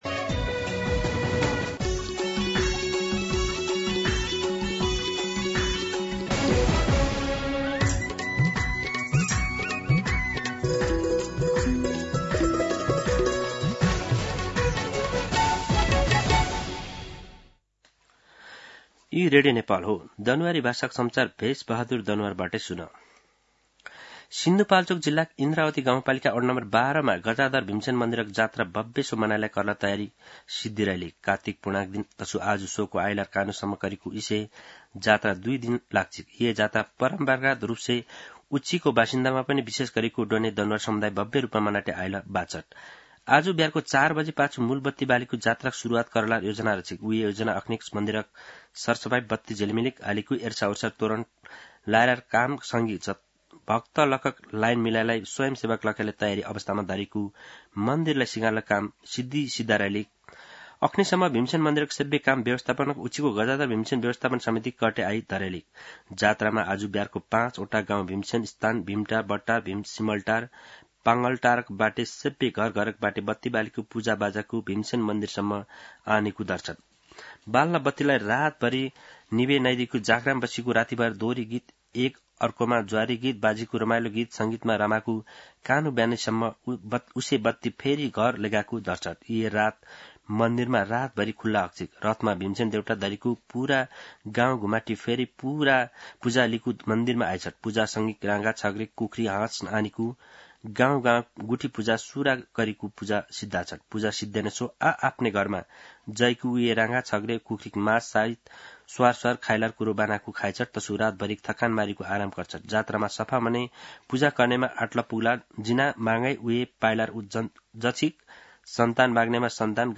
दनुवार भाषामा समाचार : १८ कार्तिक , २०८२
Danuwar-News-07-18.mp3